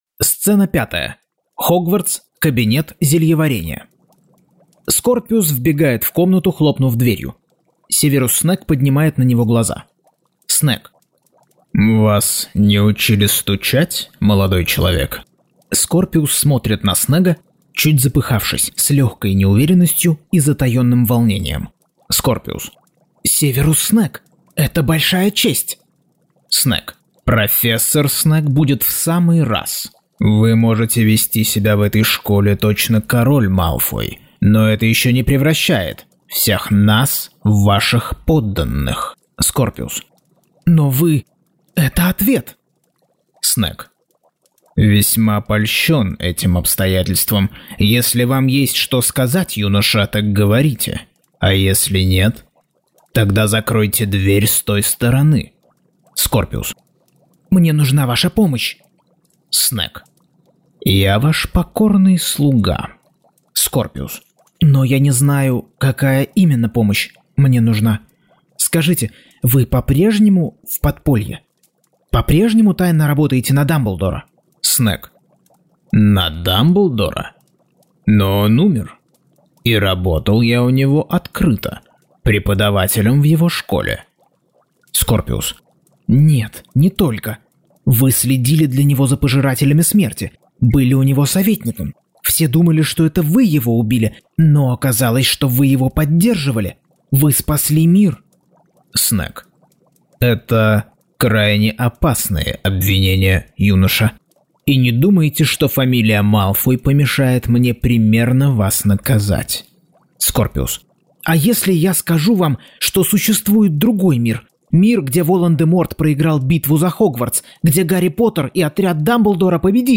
Аудиокнига Гарри Поттер и проклятое дитя. Часть 37.